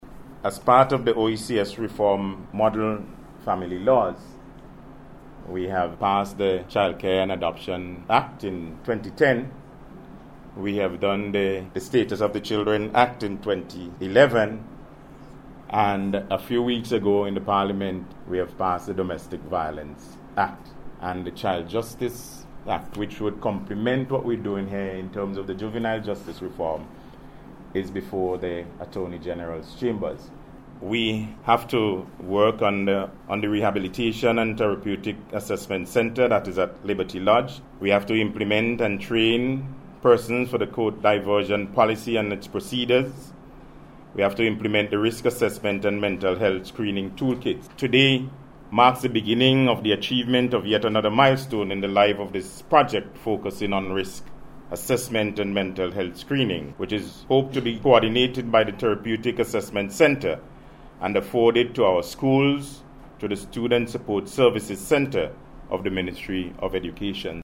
He was addressing stakeholders at the opening of the workshop on training for risk assessment and mental health screening in the Juvenile Justice Reform Program.